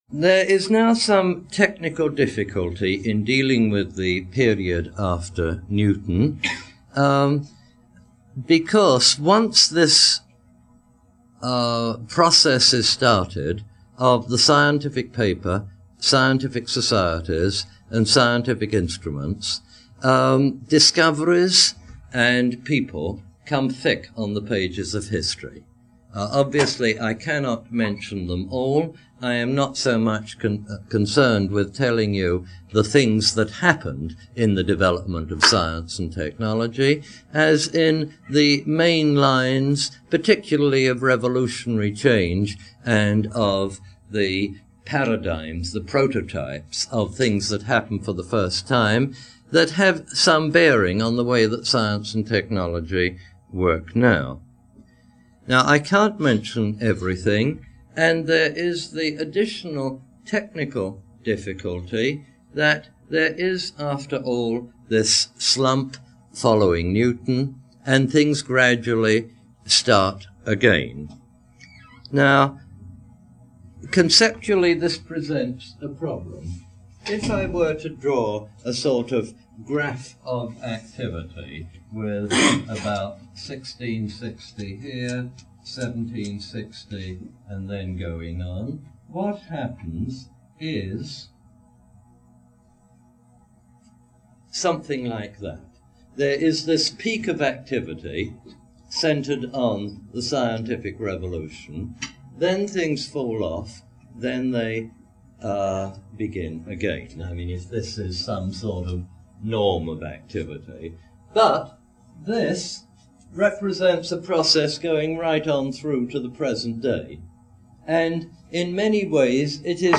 Derek de Solla Price "Neolithic to Now" Lecture #13, Yale 1976.